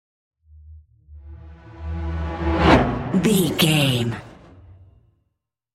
Whoosh electronic fast
Sound Effects
Atonal
Fast
futuristic
high tech
intense